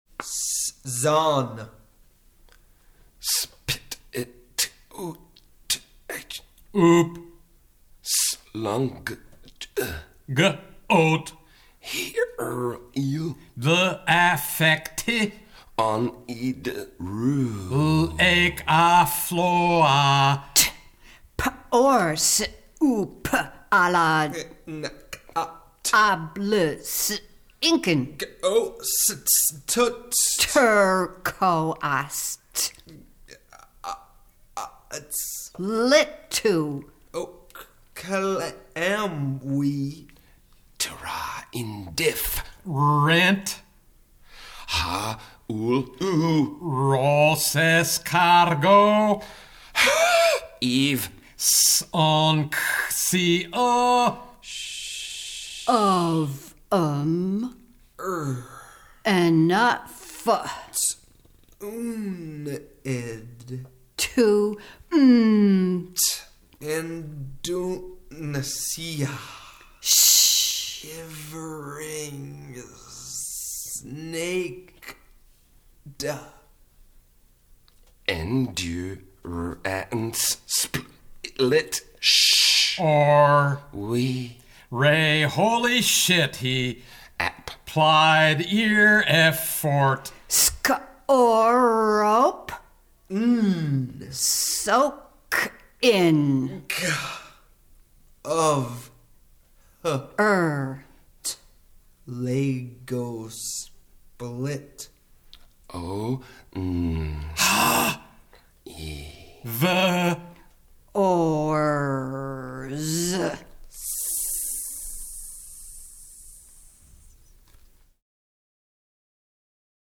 hear the Consort read the visual poem (2.3 megs)